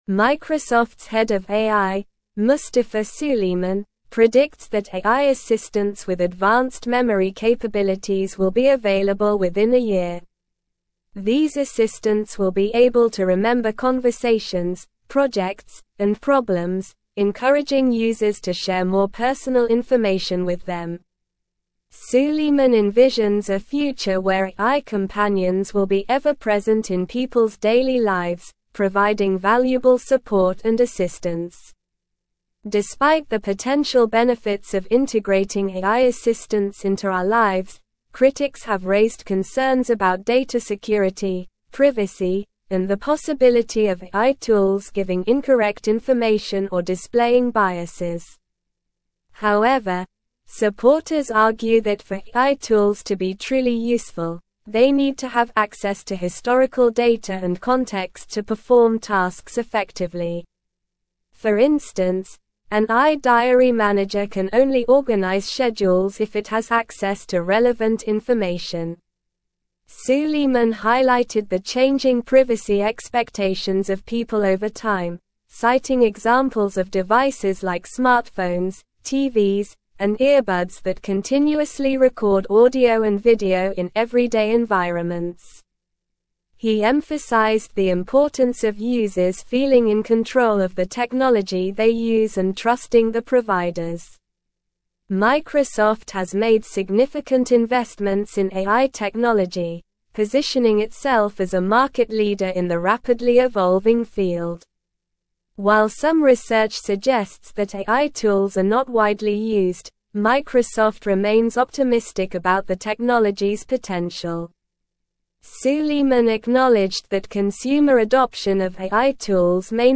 Slow
English-Newsroom-Advanced-SLOW-Reading-Microsofts-Head-of-AI-Predicts-Advanced-Memory-Assistants.mp3